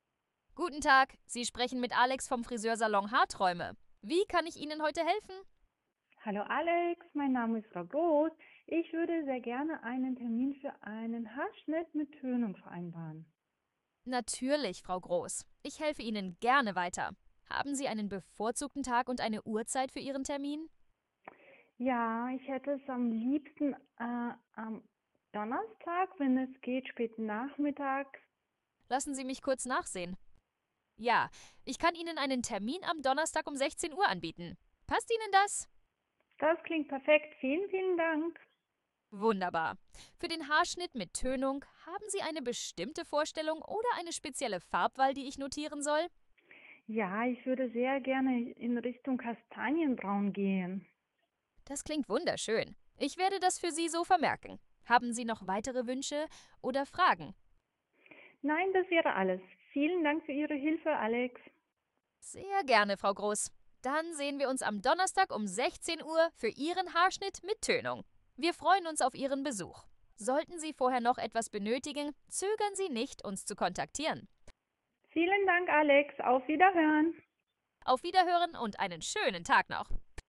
Beispiele von KI Tele-Mitarbeitern
Friseur_KI_Telefonat_Beispiel.mp3